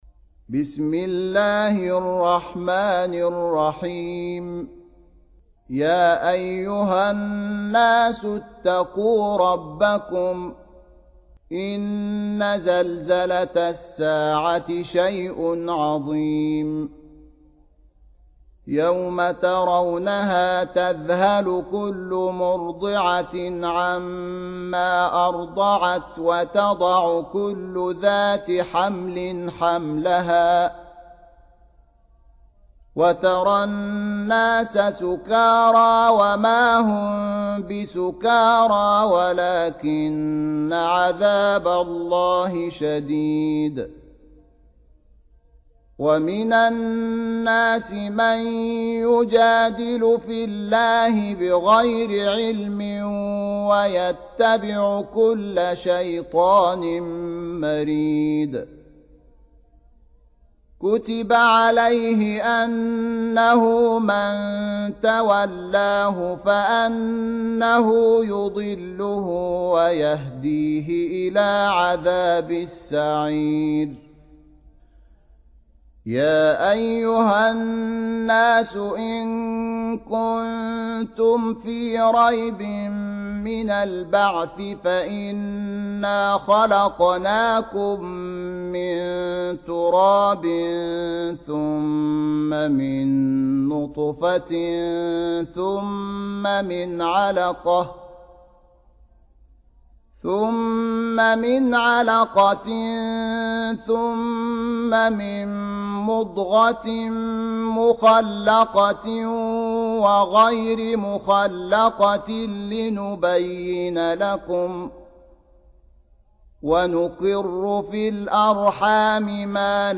Surah Repeating تكرار السورة Download Surah حمّل السورة Reciting Murattalah Audio for 22. Surah Al-Hajj سورة الحج N.B *Surah Includes Al-Basmalah Reciters Sequents تتابع التلاوات Reciters Repeats تكرار التلاوات